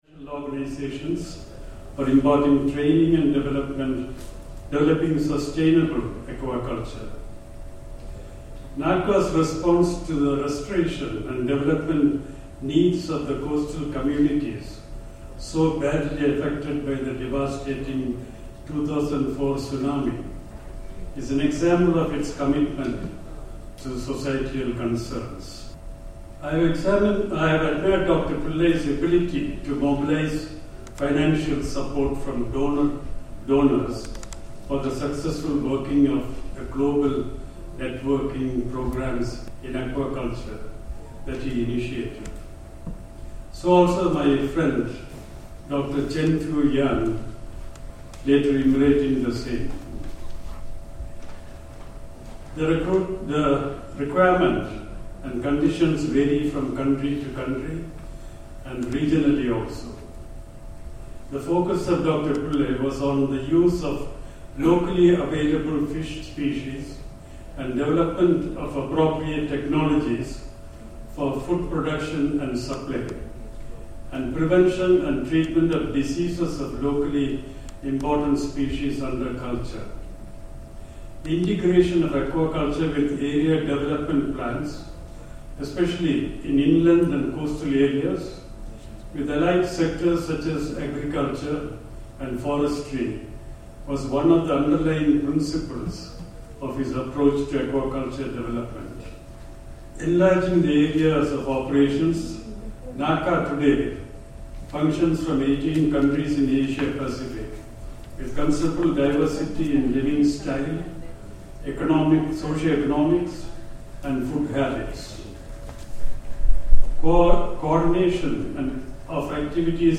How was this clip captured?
The presentations were made at the 22nd NACA Governing Council Meeting in Cochi, Kerala, from 9-11 May 2011, India.